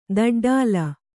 ♪ daḍḍāla